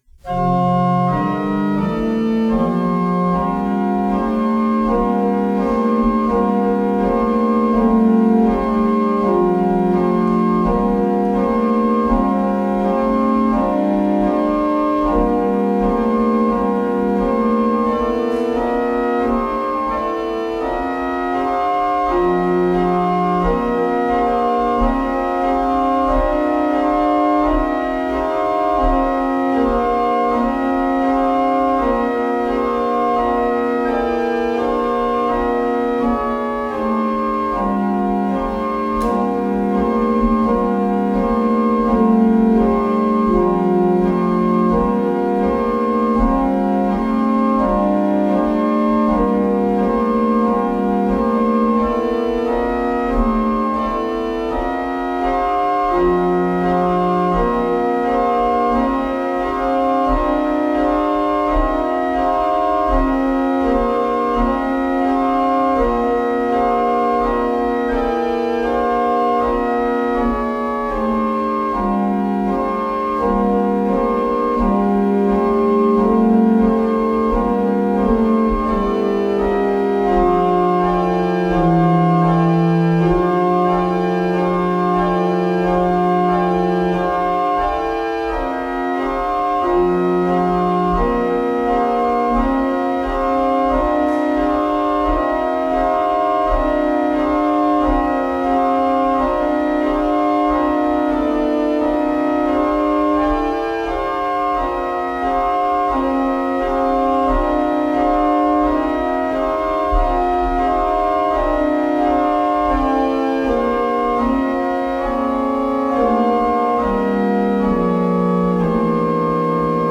On the Organ ofSt Margaret’s Church, Crick
On the Organ of St Mary’s Church, Finedon